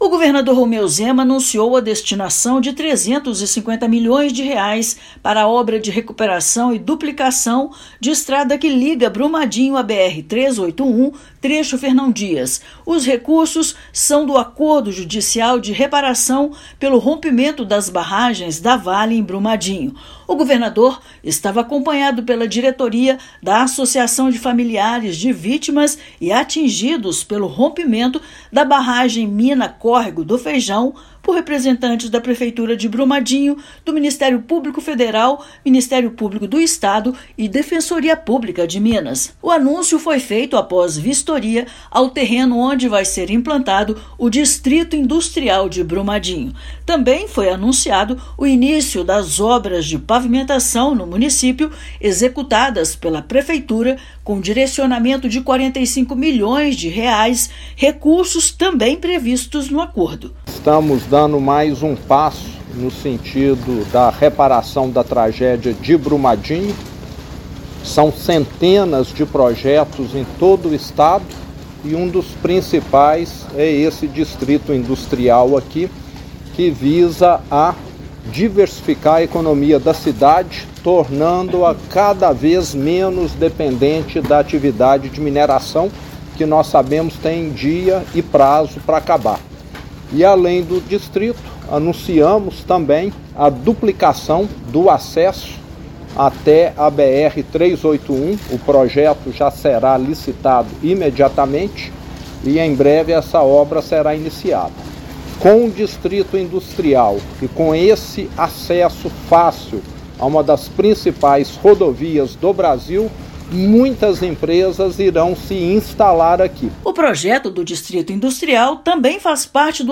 Com recursos previstos no Acordo Judicial, serão realizadas intervenções para recuperação e duplicação do acesso do município à BR-381 e, ainda, para pavimentação de estrada que liga distritos da região. Ouça matéria de rádio.